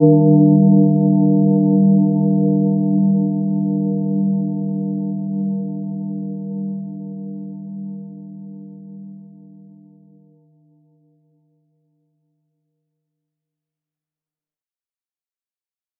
Gentle-Metallic-2-G3-mf.wav